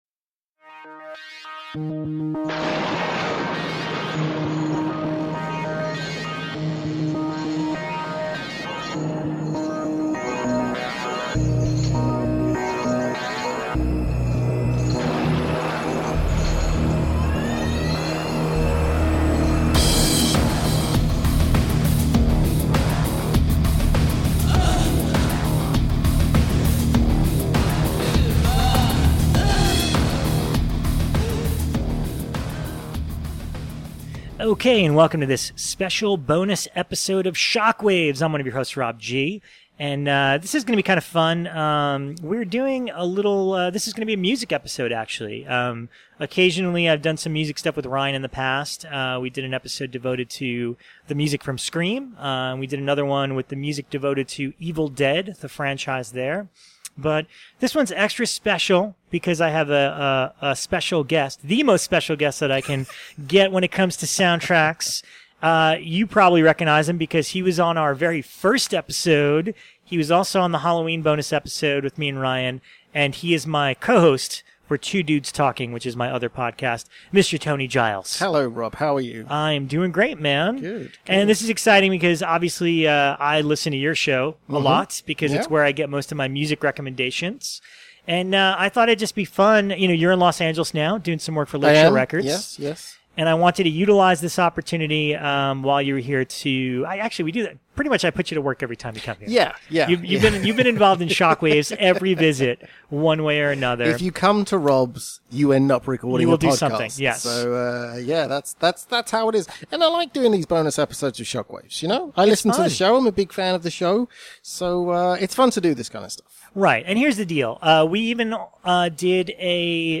horror themed soundtracks and score music